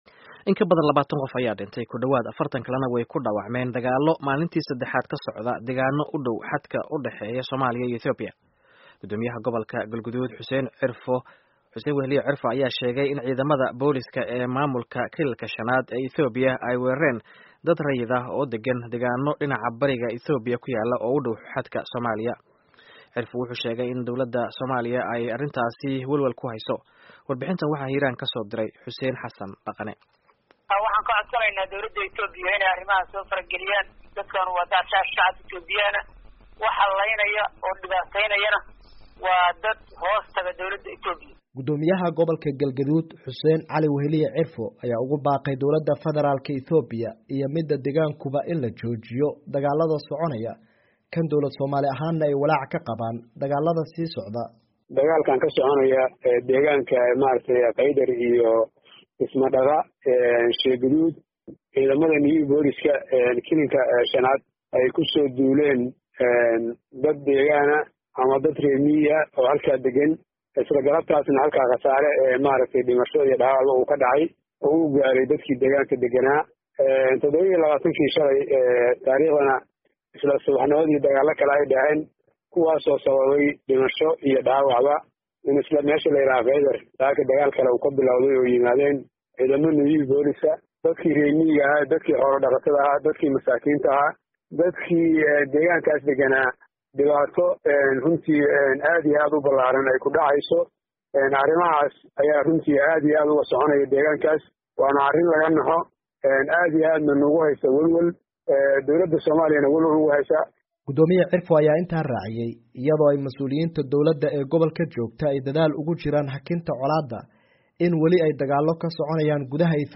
Warbixintan waxaa Hiiraan ka soo diray